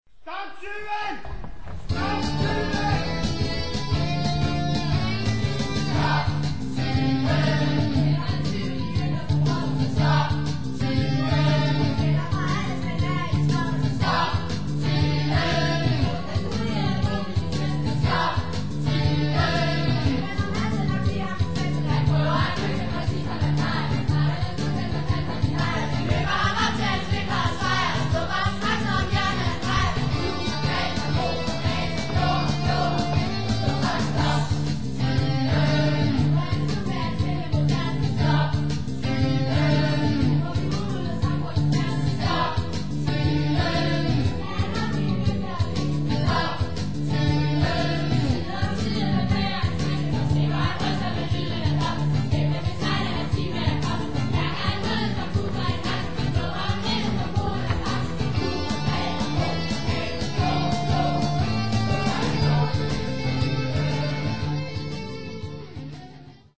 Vi hører det ene store svingende nummer efter det andet.
"live on stage!"